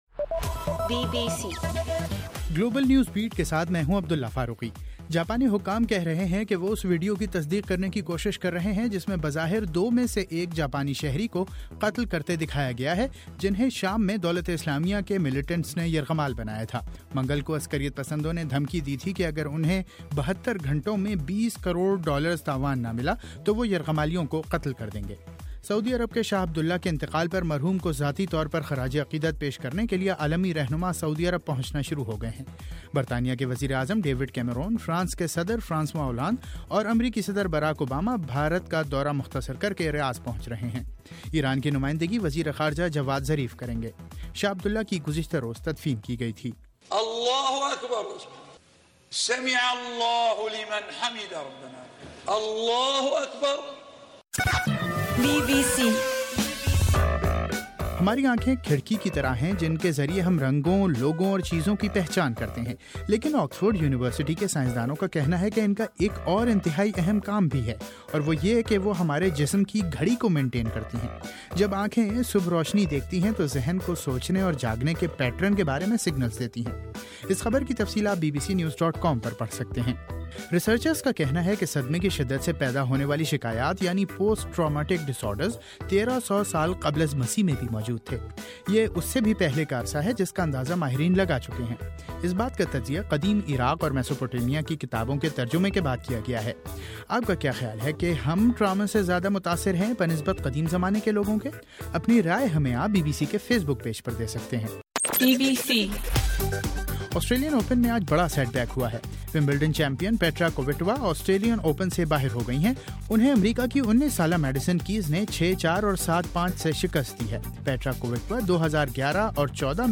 جنوری 24: رات 10 بجے کا گلوبل نیوز بیٹ بُلیٹن